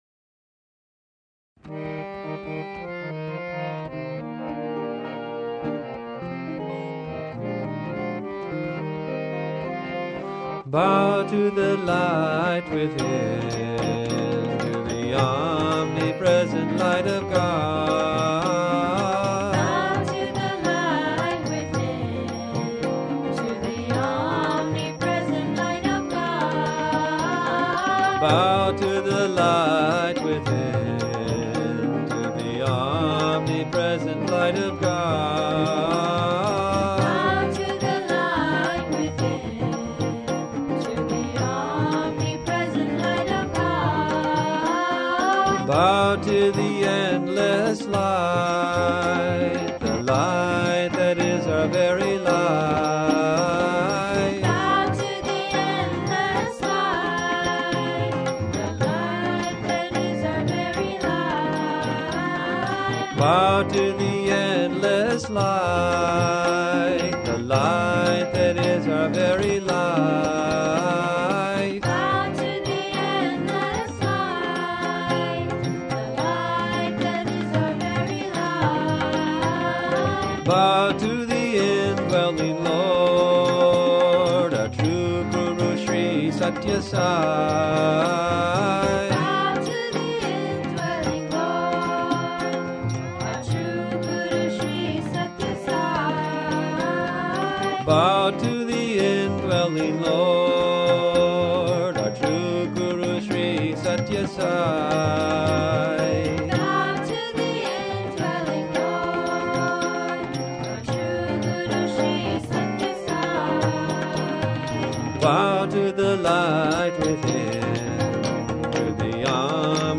1. Devotional Songs
Major (Mohanam / Bhoop)
8 Beat / Keherwa / Adi
Medium Fast
6 Pancham / A
3 Pancham / E
Lowest Note: p / G (lower octave)
Highest Note: P / G